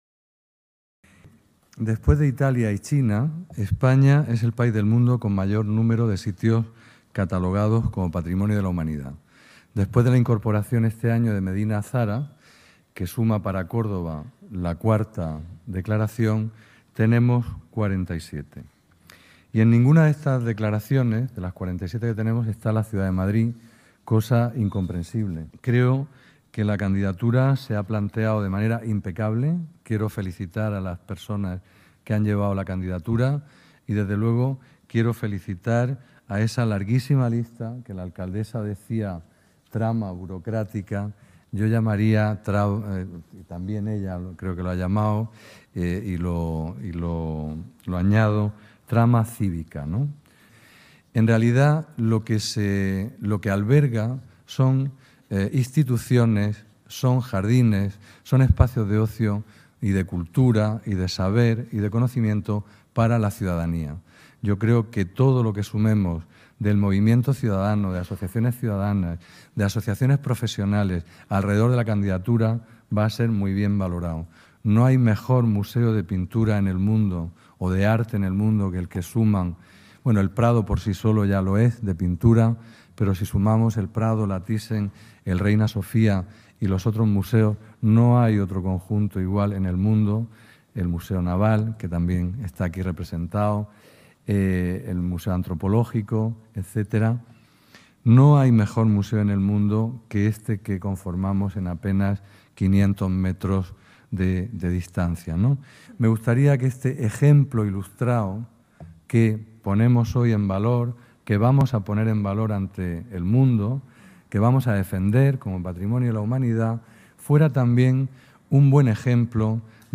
En ese sentido, el ministro de Cultura y Deportes, José Guirado, ha cerrado con el deseo de que “este ejemplo de patrimonio que ponemos delante del mundo, sea también un buen ejemplo de crecimiento ilustrado, que la próximas generaciones puedan sentirse orgullosas de lo que hicimos.
José Guirao, ministro de Cultura y Deportes